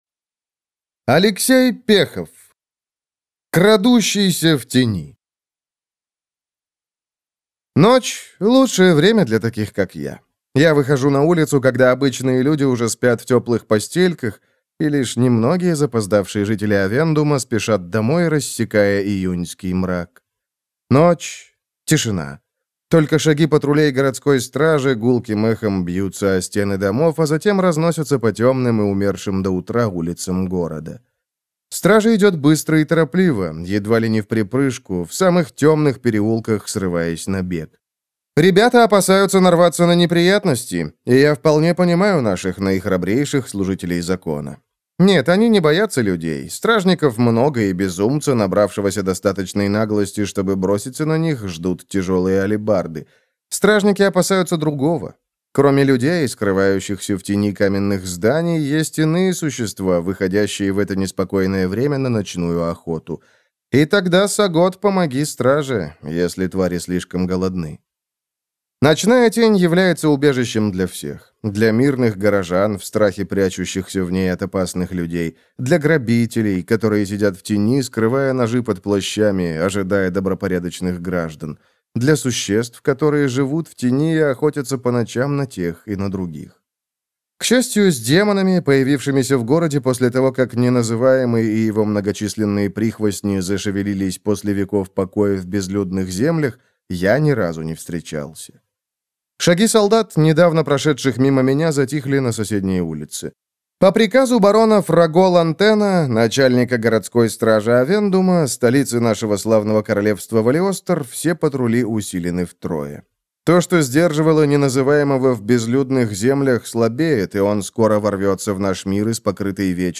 ОБХСС-82. Финал (слушать аудиокнигу бесплатно) - автор Павел Барчук